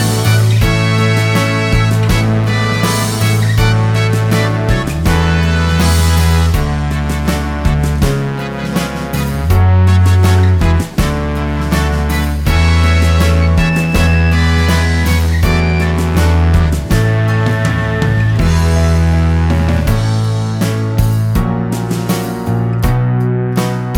No Backing Vocals Soundtracks 2:40 Buy £1.50